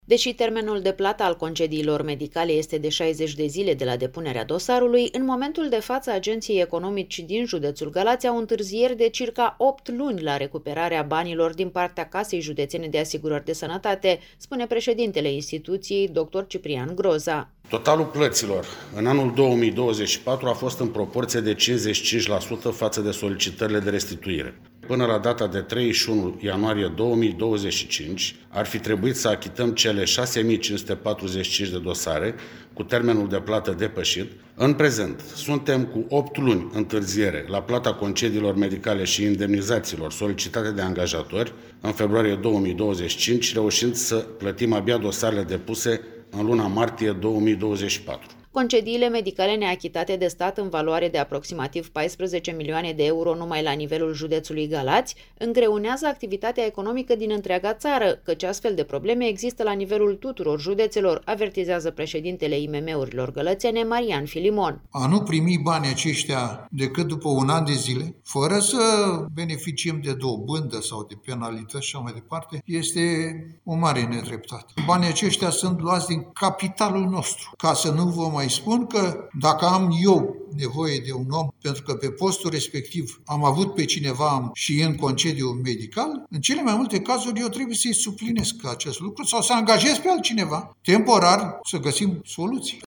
Corespondenta RRA